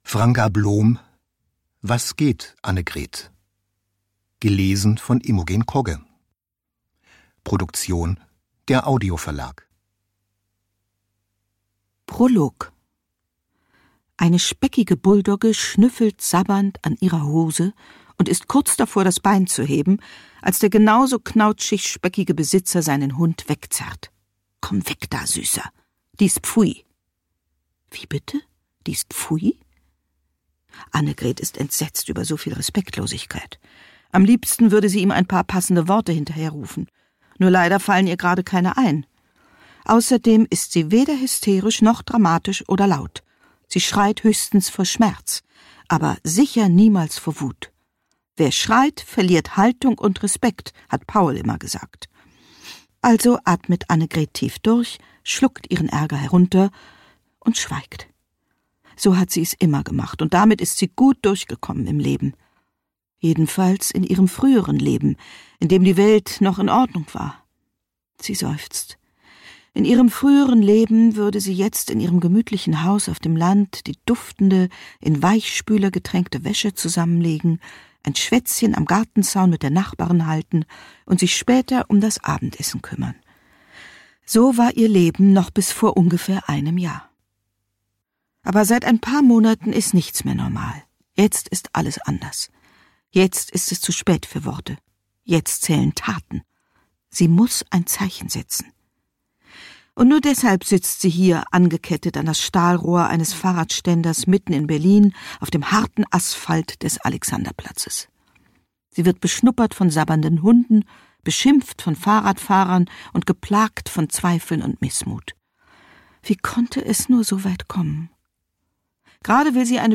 Ungekürzte Lesung mit Imogen Kogge (1 mp3-CD)
Imogen Kogge (Sprecher)